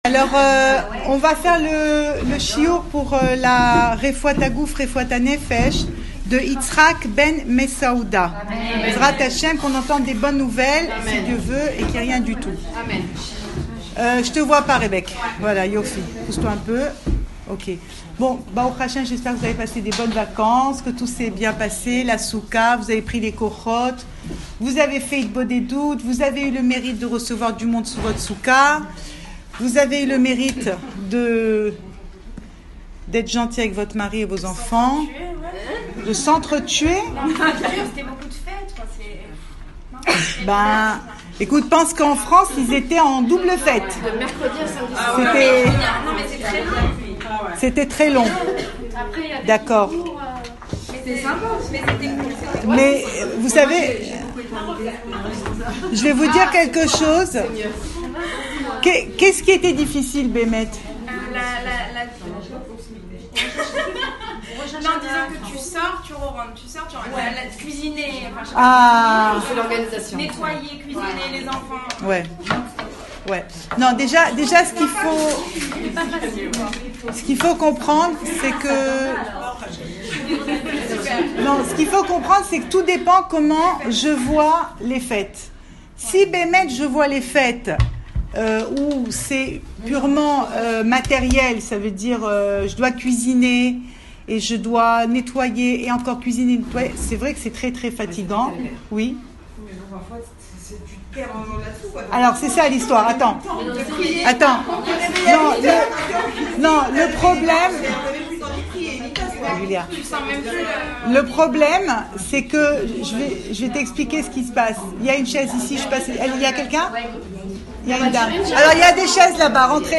Cours audio Le coin des femmes Pensée Breslev Vie de couple - 17 octobre 2017 19 octobre 2017 Modalités post infidélité pour éviter les divorces… Enregistré à Raanana